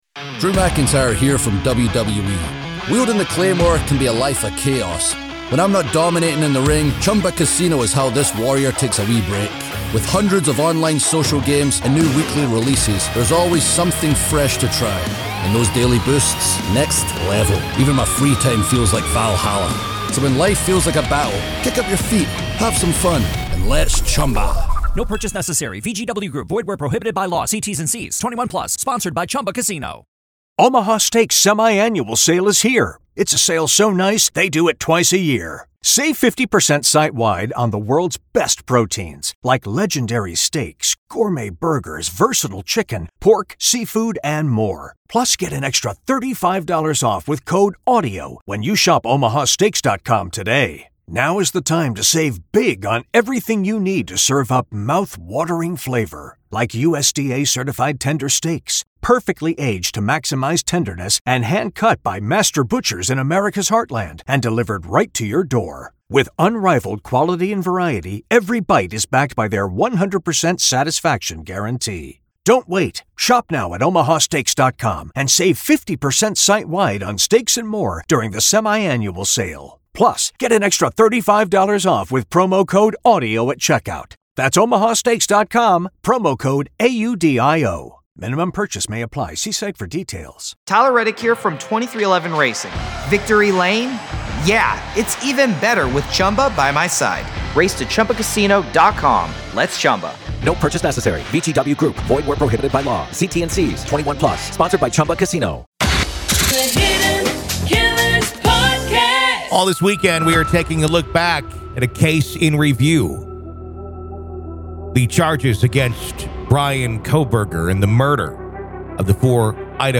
Join us on an intellectual exploration as we delve into the enigmatic world of true crime, peering into the minds of criminals through captivating interviews and profoun...